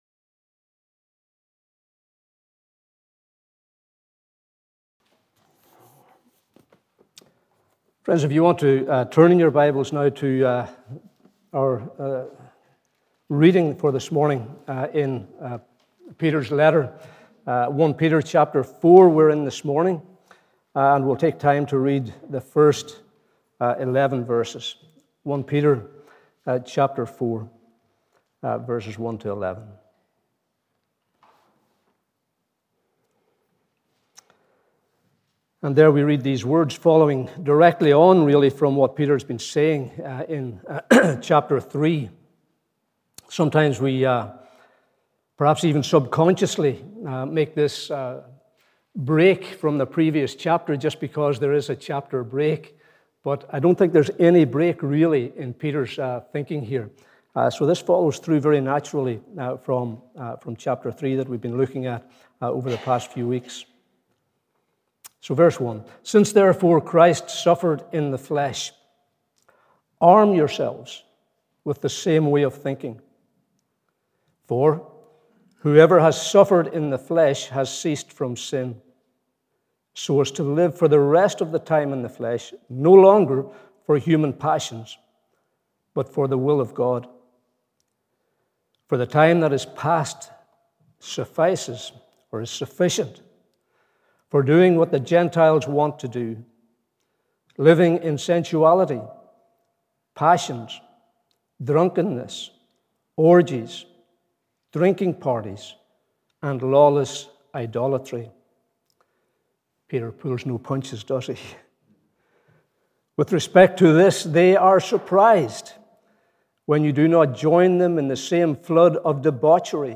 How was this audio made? Morning Service 29th May 2022